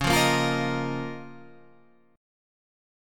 C#6add9 chord